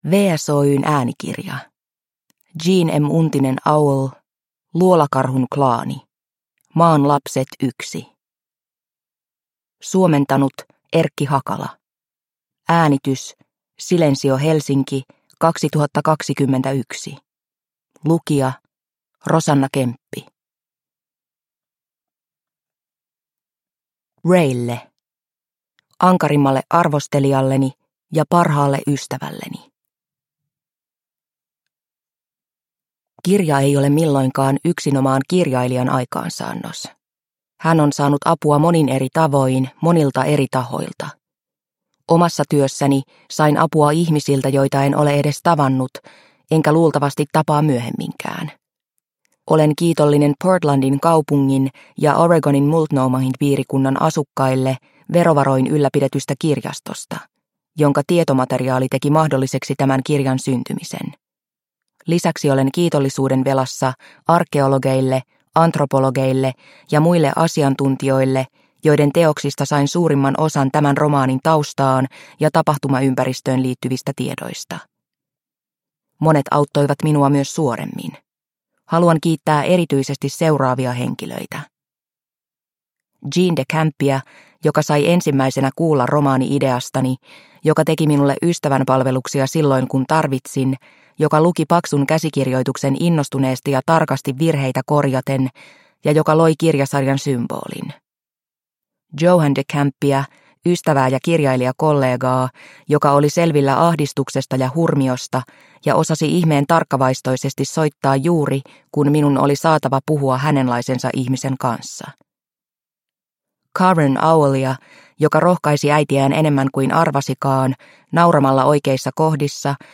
Luolakarhun klaani – Ljudbok – Laddas ner